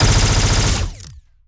Added more sound effects.
GUNAuto_Plasmid Machinegun B Burst_06_SFRMS_SCIWPNS.wav